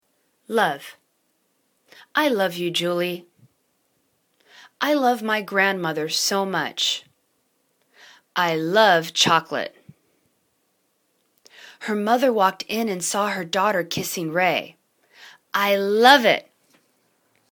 love    /luv/   v